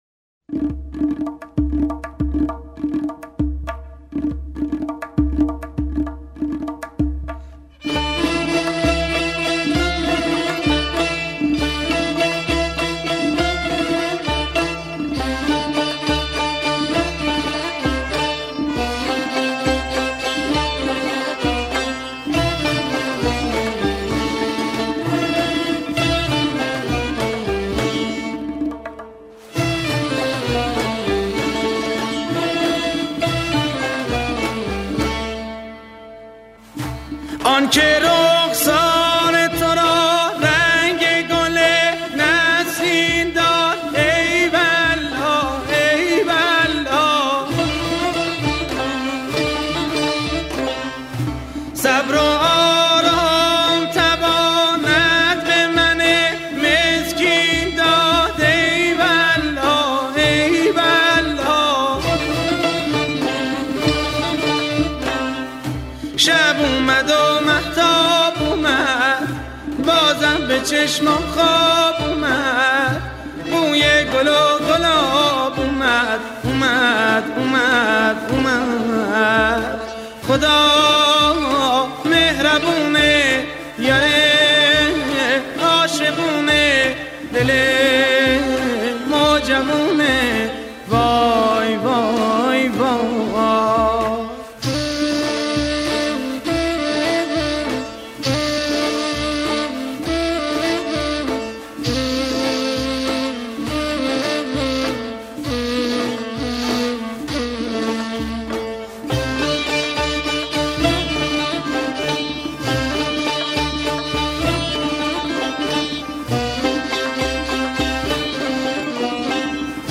استودیو : بل
سبک : موسیقی محلی شیرازی